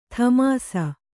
♪ thamāsa